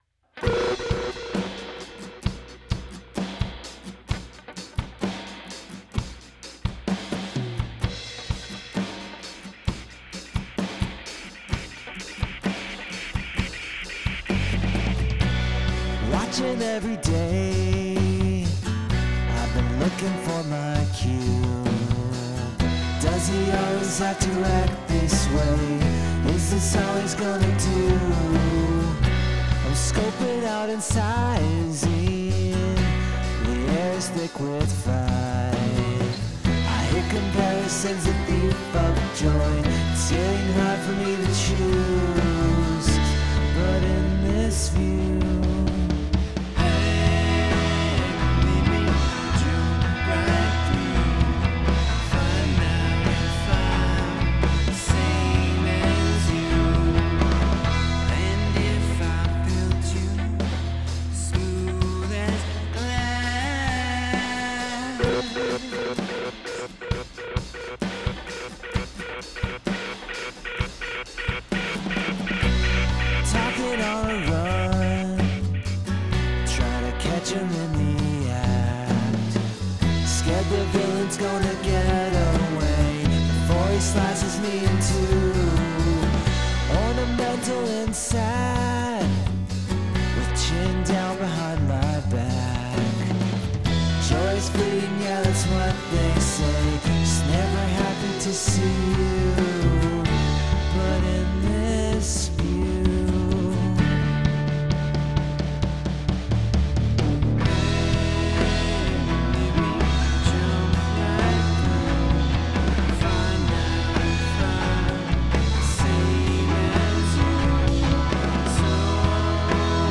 That bloopy dub sound is great.